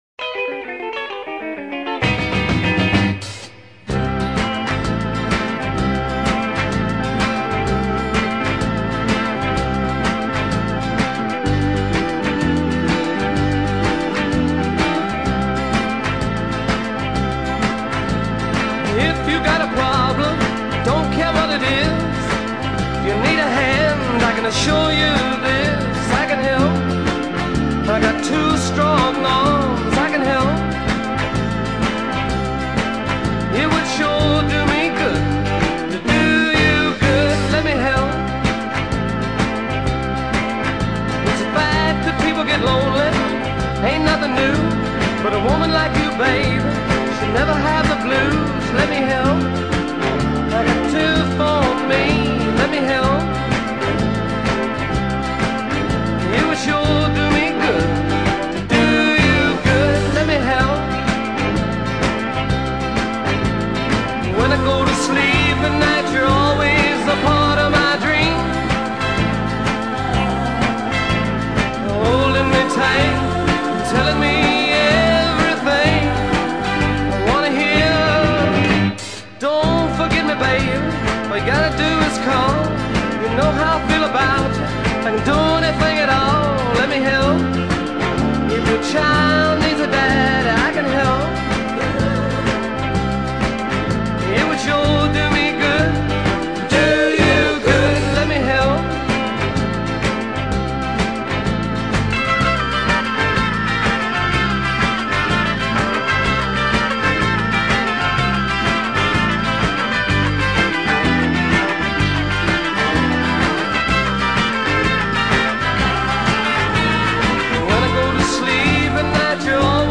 falso final de órgano hammond